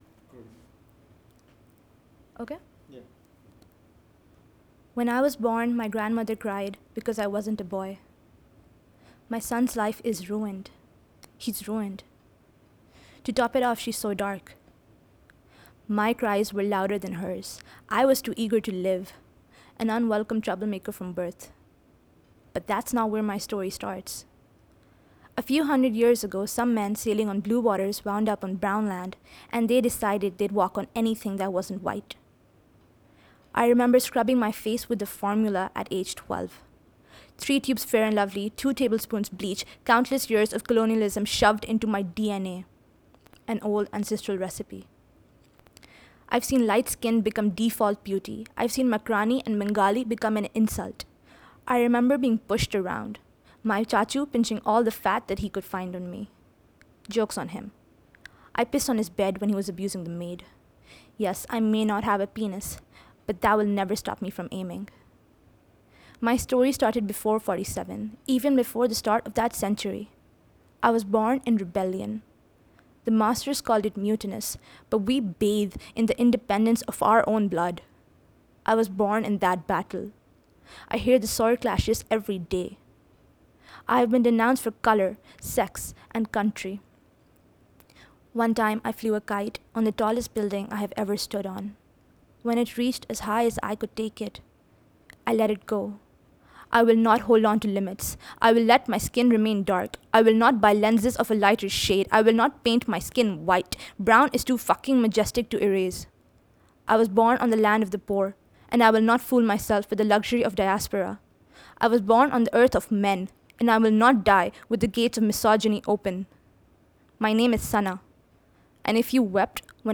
“Orbs and Rebellion” – Slam Poetry